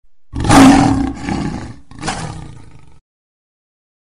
Wolf Howl
Wolf Howl is a free animals sound effect available for download in MP3 format.
342_wolf_howl.mp3